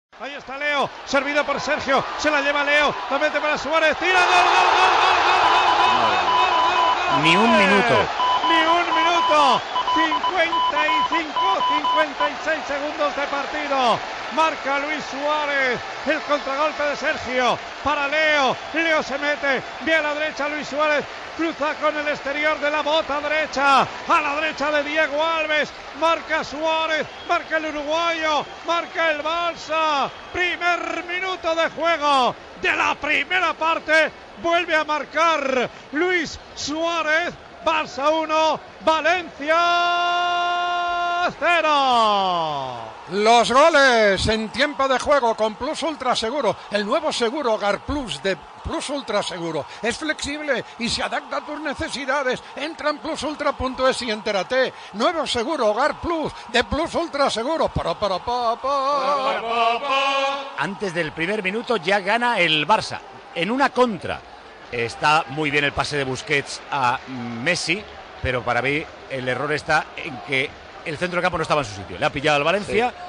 Transmissió del partit de la lliga masculina de futbol entre el Futbol Club Barcelona i el València Club de Futbol.
Narració del gol de Luis Suárez, publicitat i valoració de la jugada.
Esportiu